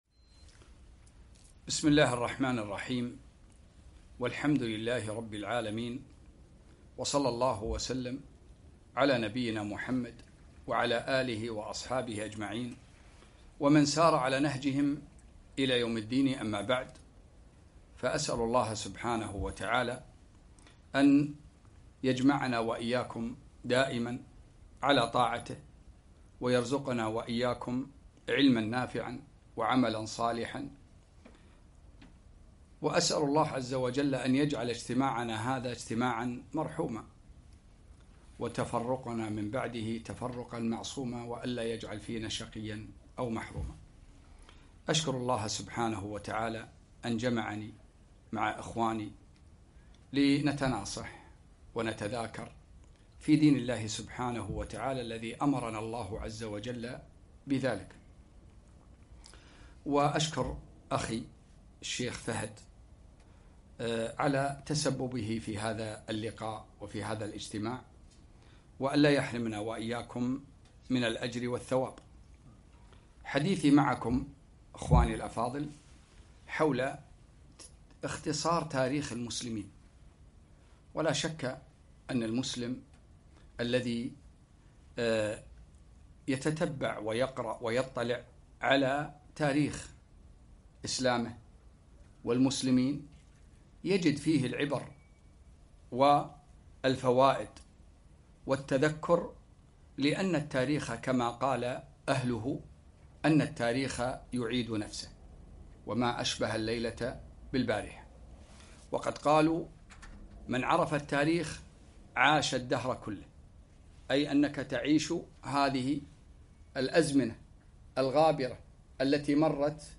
1- السيرة النبوية في محاضرة واحدة بعثة الرسول بداية التاريخ الإسلامي